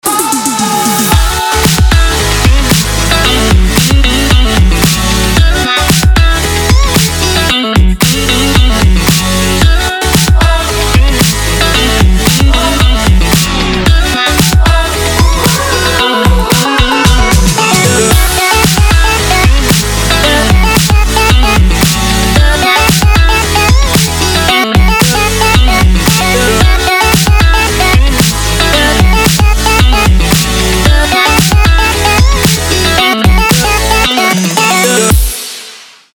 • Качество: 320, Stereo
club
house
electro
Slow Electro